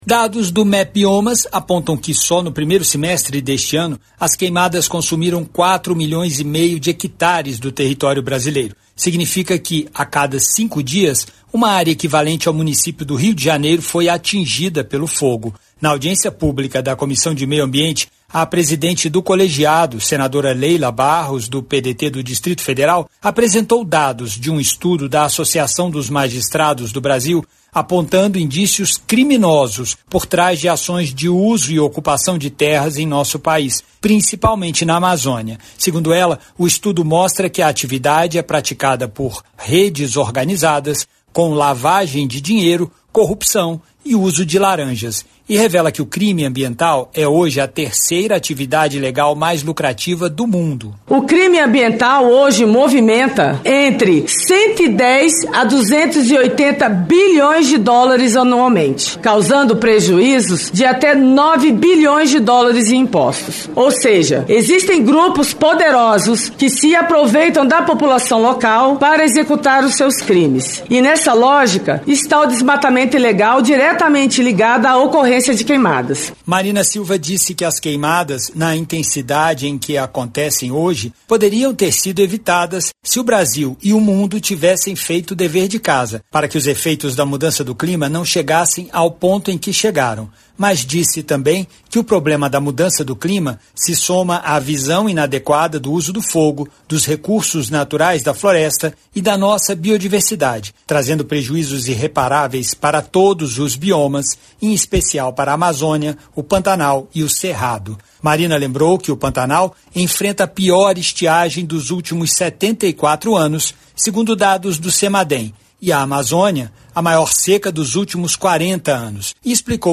A Comissão de Meio Ambiente ouviu nesta quarta-feira (4) a ministra do Meio Ambiente e Mudança do Clima, Marina Silva. Ela apresentou informações sobre a situação das queimadas em todo o país e as medidas que estão sendo adotadas pelo governo federal para enfrentá-las.